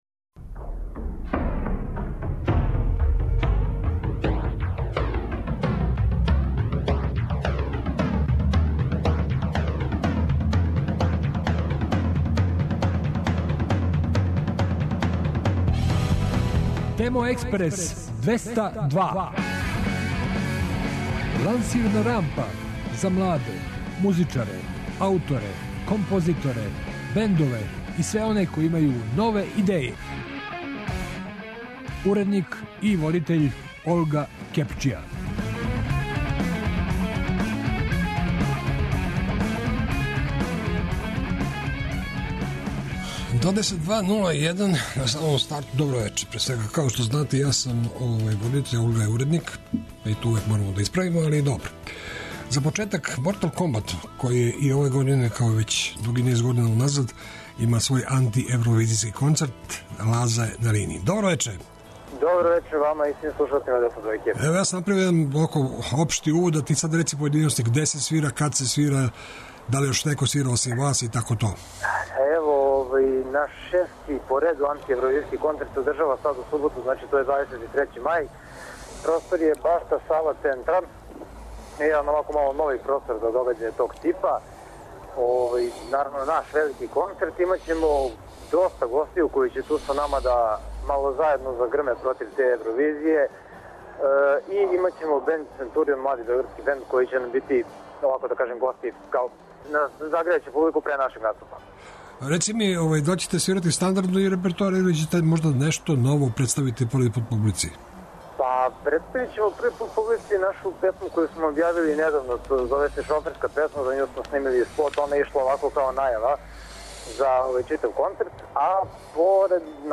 Ова и наредне 4 емисије ће се прилично разликовати од претходних - у свакој ћемо имати госте- финалисте Бунт рок мастерса који ће за вас из студија 21 свирати "уживо".